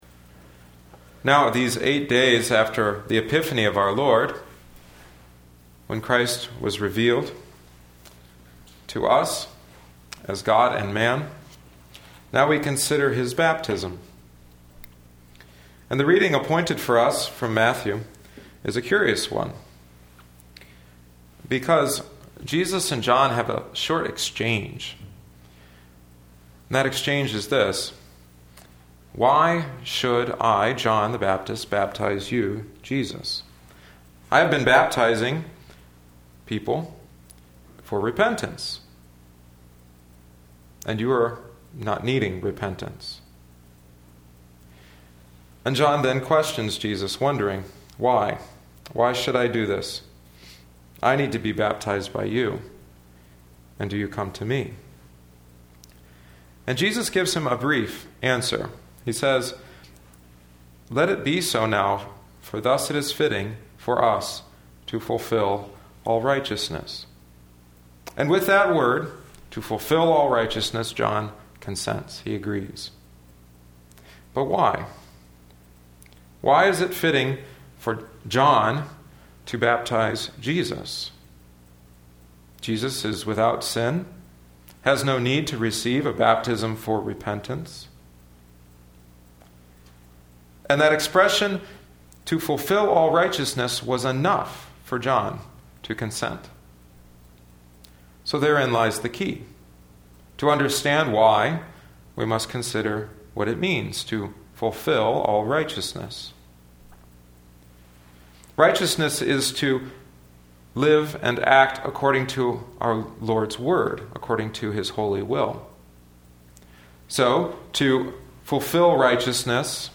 Listen: Divine Service 2012-01-13